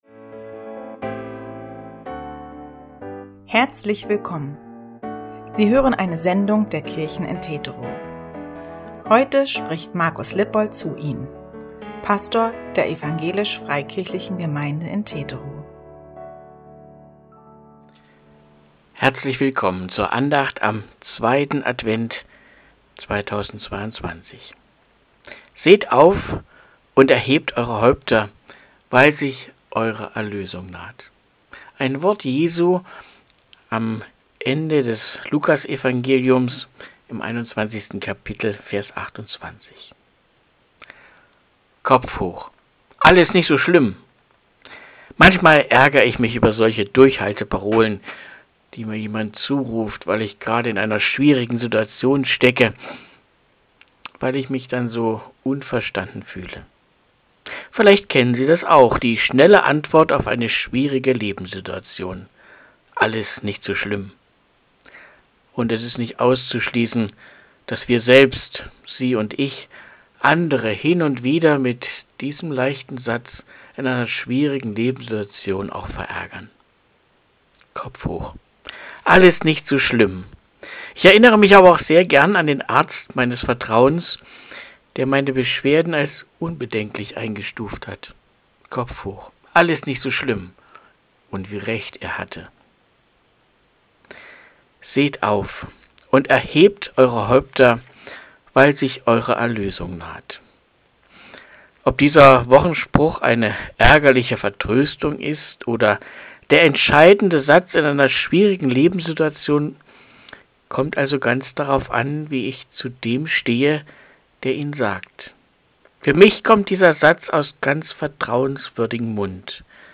Audio-Andacht 04.12.2022